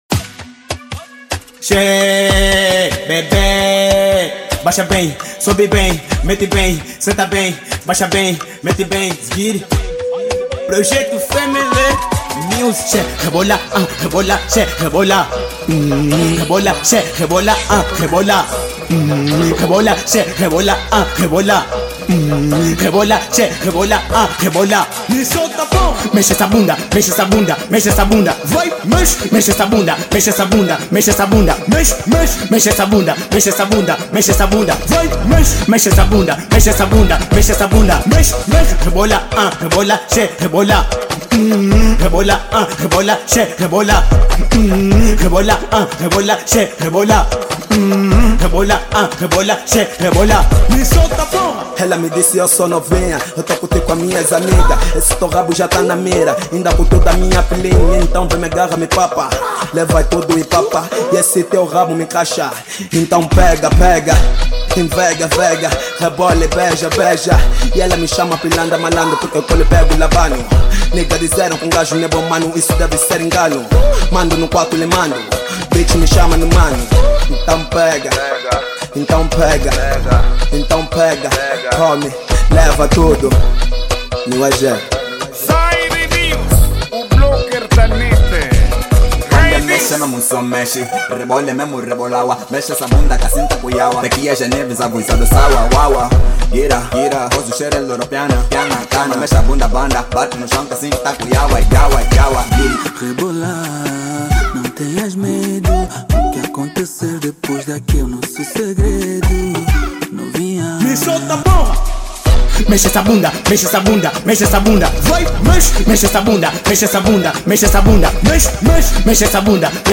Gênero:Rap